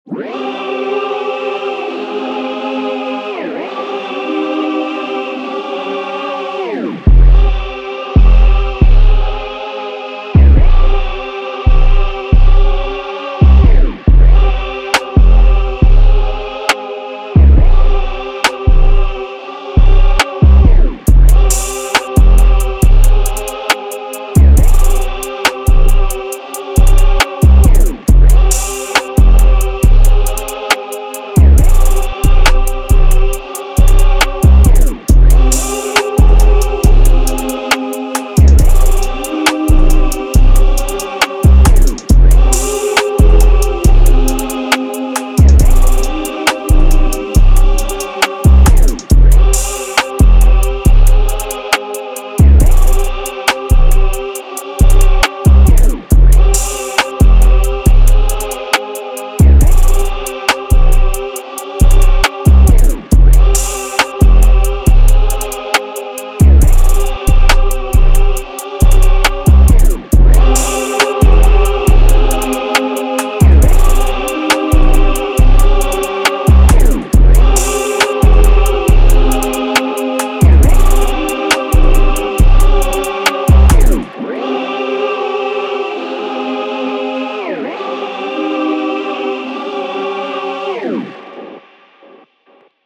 Hip Hop, Rap
F Minor
Anthemic, motivational track